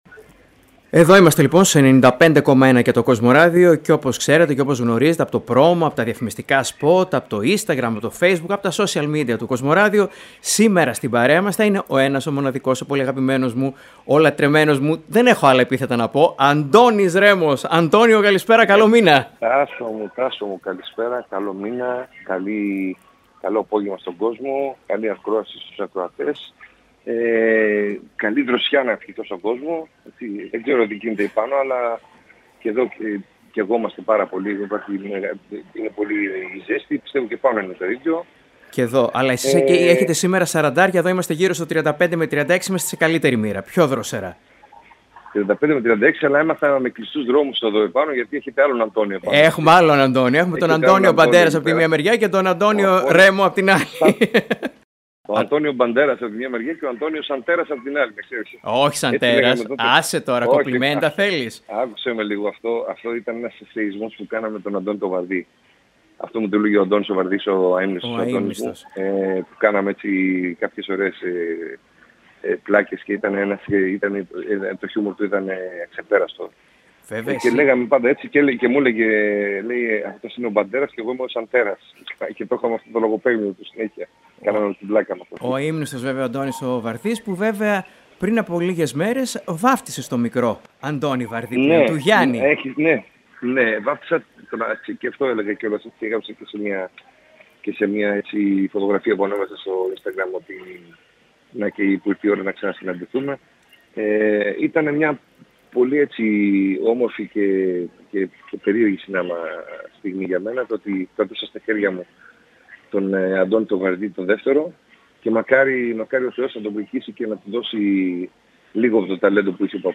Μία άκρως ενδιαφέρουσα συνέντευξη παραχώρησε ο Αντώνης Ρέμος στο Κοσμοράδιο 95,1